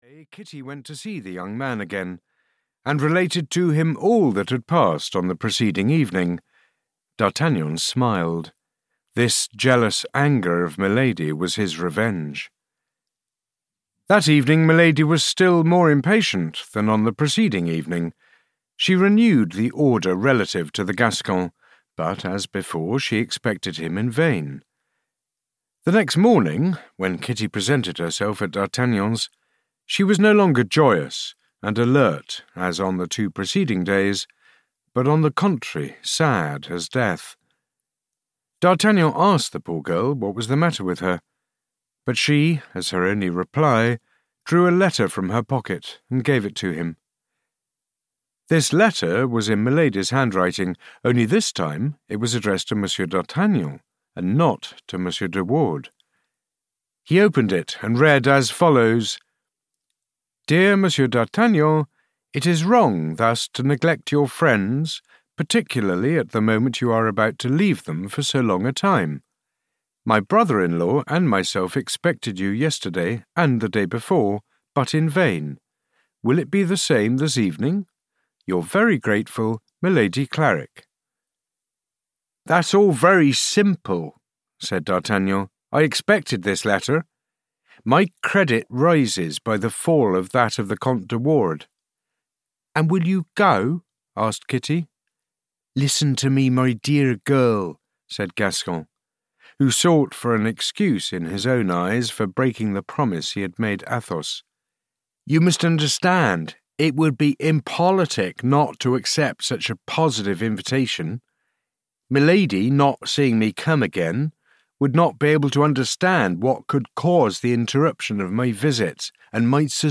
The Three Musketeers III (EN) audiokniha
Ukázka z knihy